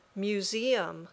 museum.mp3